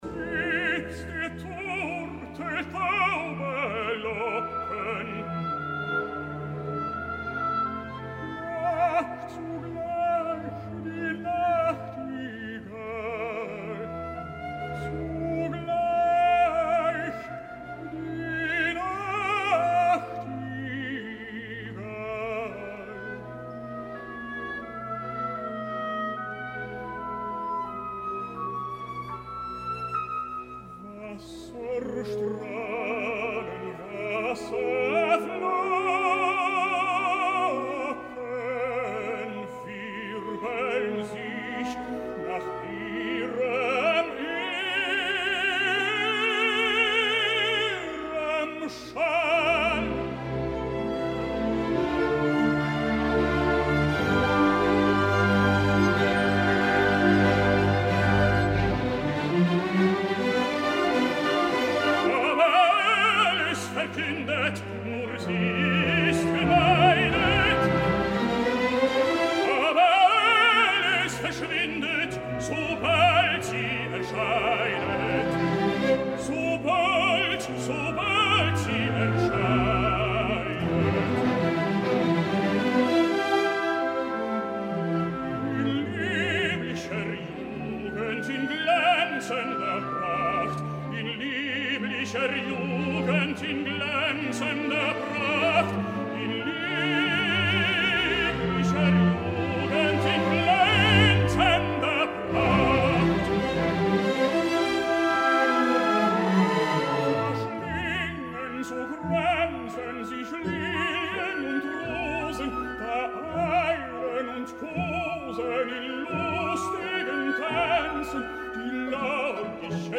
Aquest cap de setmana es reunien a la sala Philharmonie de Berlín, el director milanés, la jove mezzosoprano Christianne Stotijn que fa molt poc ja ens va visitar i un dels estels operístics del moment, el tenor Jonas Kaufmann, declarat per la revista Opernwelt, millor cantant de l’any, cosa que estic segur que molts compartim.
Escoltarem un petit fragment, des de “Mit der Turteltaube Locken”, on podrem apreciar a un Kaufmann en un estat vocal magnífic.
Rinaldo, Kantate für Tenor-Solo, Männerchor und Orchester op. 50